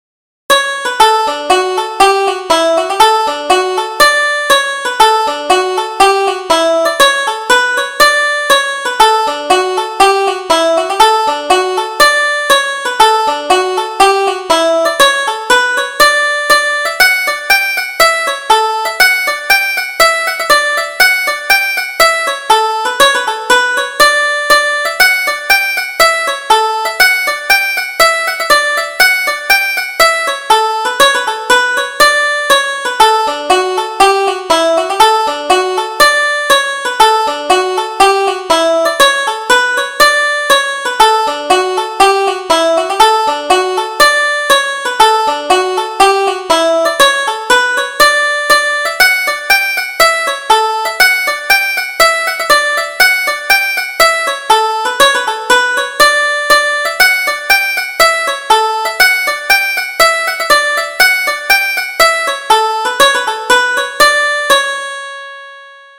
Irish Traditional Polkas